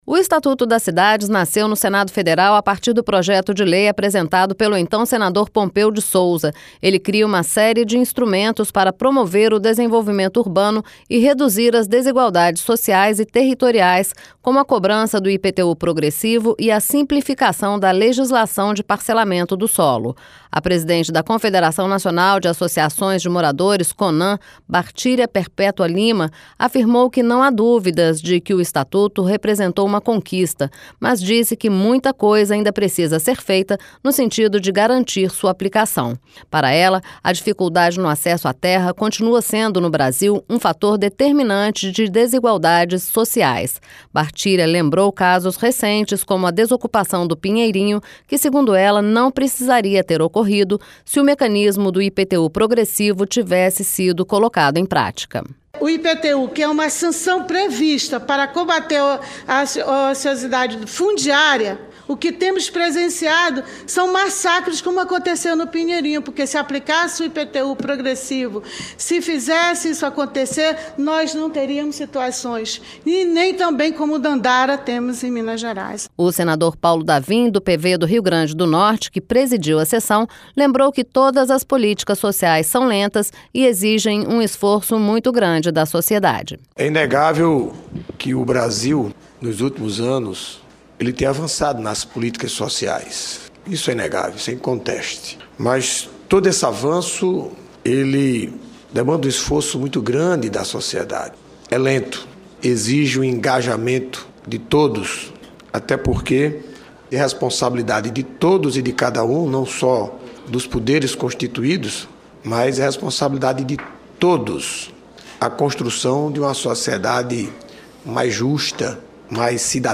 LOC: DEPOIS DE MAIS DE DEZ ANOS DA CRIAÇÃO DO ESTATUTO DAS CIDADES AINDA HÁ MUITAS DIFICULDADES PARA COLOCAR EM PRÁTICA INSTRUMENTOS PREVISTOS NA LEI. LOC: FOI O QUE RELATARAM OS CONVIDADOS PARA A AUDIÊNCIA PÚBLICA QUE ACONTECEU NA COMISSÃO DE DIREITOS HUMANOS QUE DEBATEU OS DEZ ANOS DO ESTATUTO.